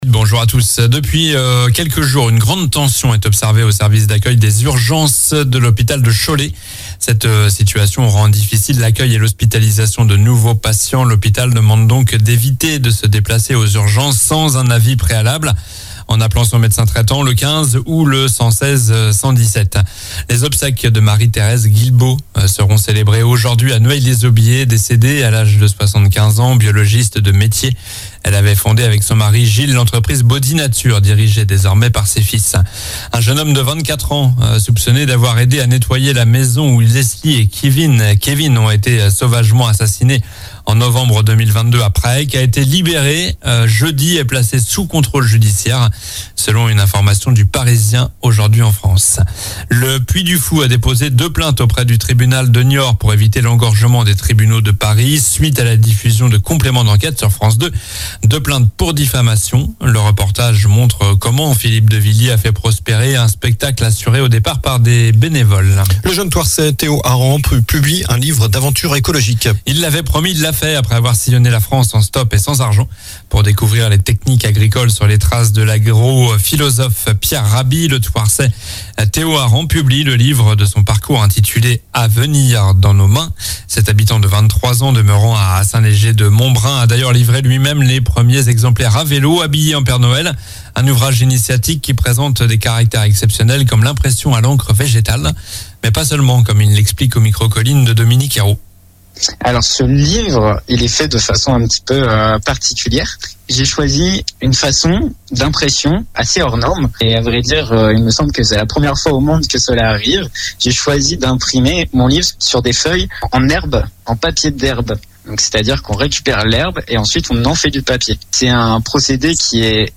Journal du samedi 06 janvier (matin)